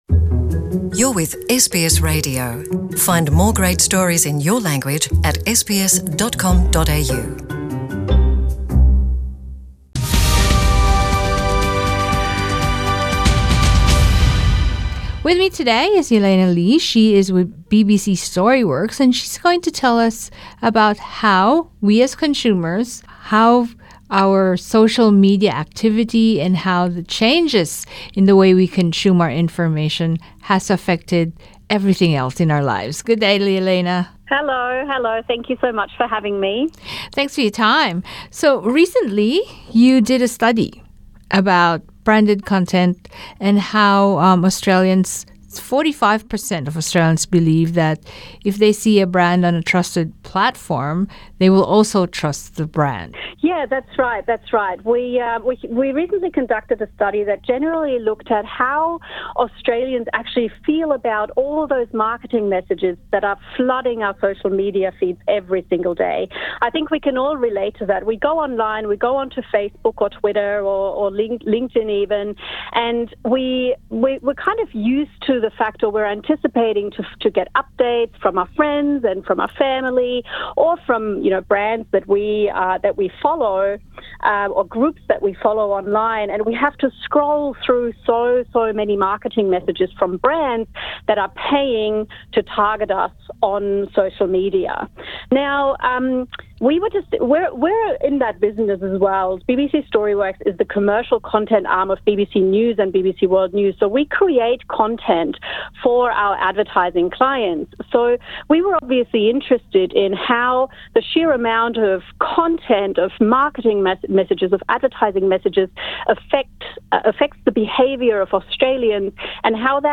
Narito ang panayam